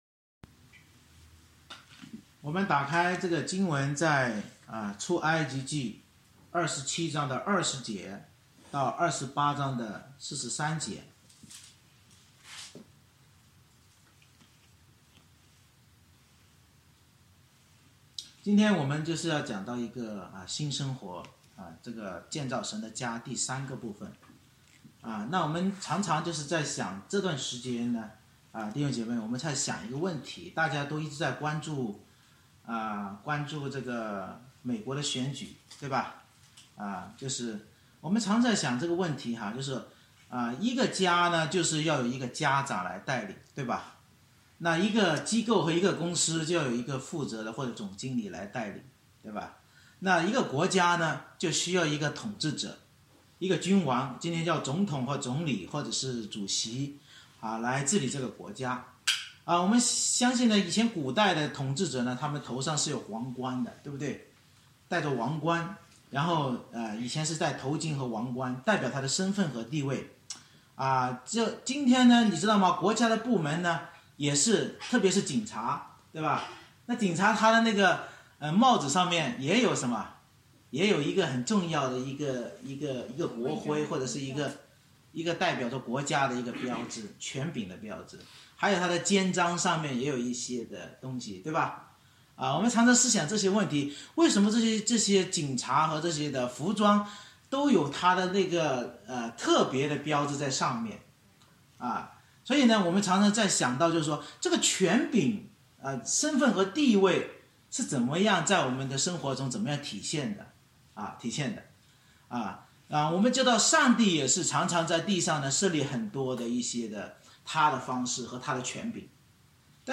November 8, 2020 （新生活）建造神的家（三）一一归主为圣 Series: 《出埃及记》讲道系列 Passage: 出埃及记27：20-28：43 Service Type: 主日崇拜 神吩咐摩西为拣选祭司制造供职圣衣归耶和华为圣，预表基督是神与人中间归神为圣和担人罪孽的大祭司，我们跟随基督担当神家祭司要穿上全副军装归主为圣。